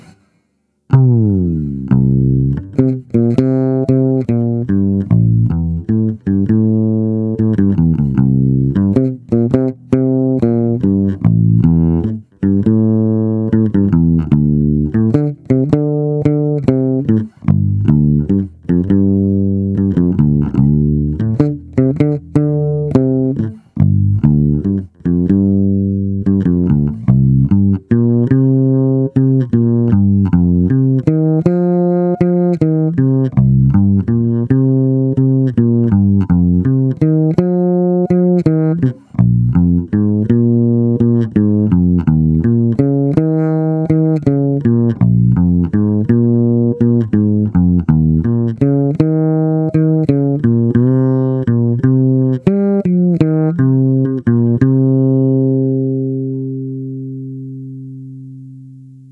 Zvuk je opravdu hutný a i díky piezo snímači dostává akustický, až kontrabasový nádech. Ostatně posuďte sami z nahrávek, které jsou pořízené přes zvukovku do PC, bez úprav.
Krkový + kobylkový
Máš moc velkou citlivost na vstupu, takže je signál ořezaný, zkreslený a proto to ve zvuku chrčí.